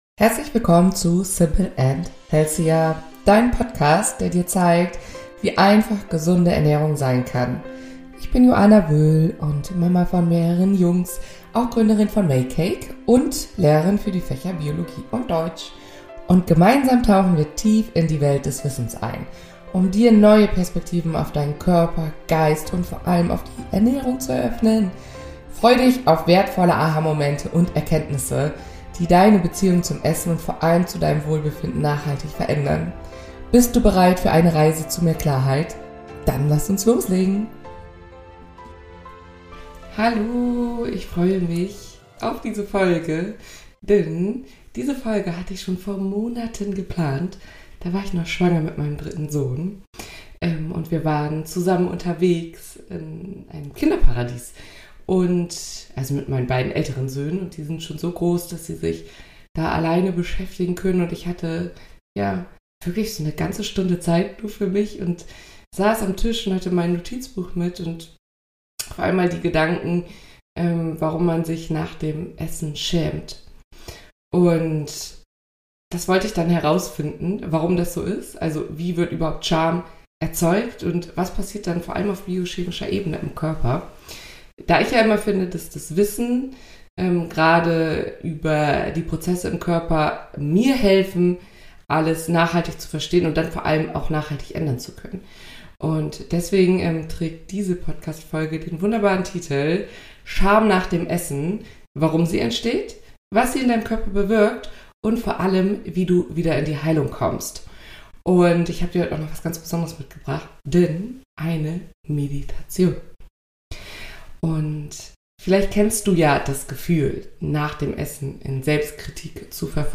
Am Ende der Folge wartet eine geführte Meditation auf dich, die dich mit dem Ursprung deiner Scham verbindet – und dich sanft zurück zu dir führt.